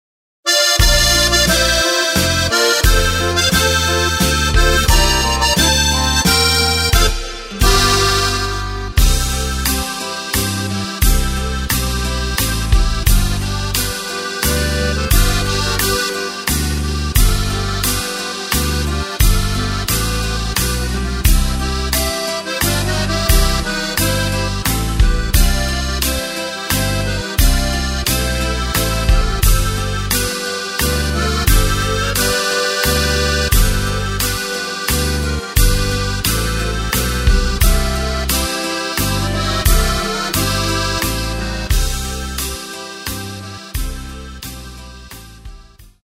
Takt:          3/4
Tempo:         88.00
Tonart:            F
Langsamer Walzer aus dem Jahr 1987!
Playback mp3 Mit Drums